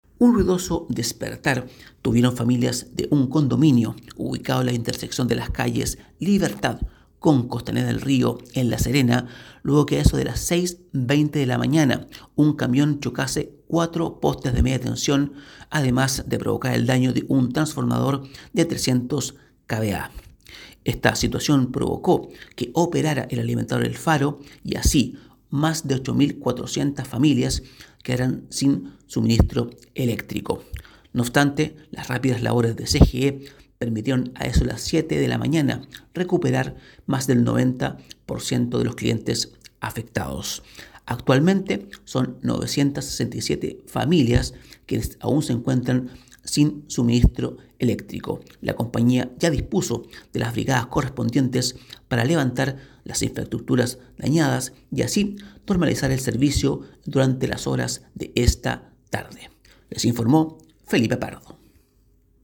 AUDIO: Despacho